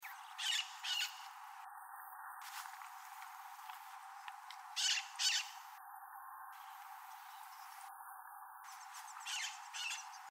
Calancate Ala Roja (Psittacara leucophthalmus)
Nombre en inglés: White-eyed Parakeet
Fase de la vida: Adulto
Localidad o área protegida: Reserva Ecológica Costanera Sur (RECS)
Condición: Silvestre
Certeza: Fotografiada, Vocalización Grabada